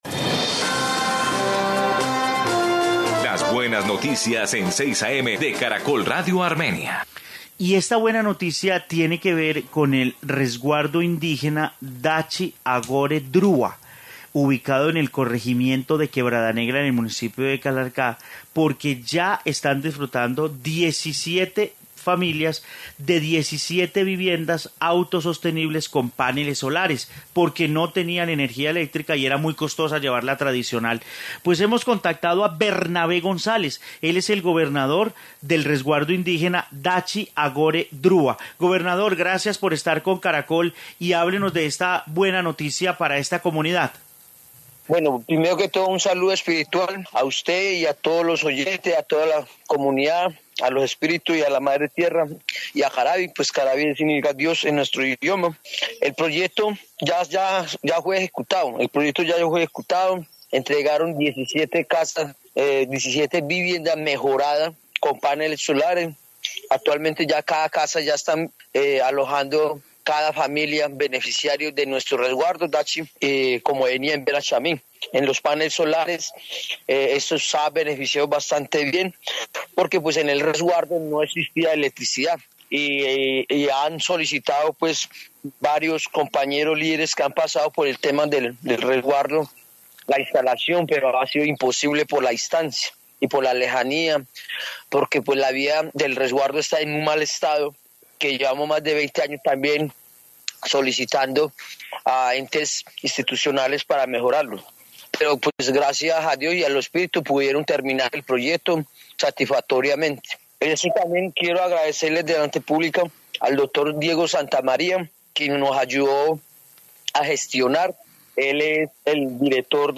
Informe indígenas Quindío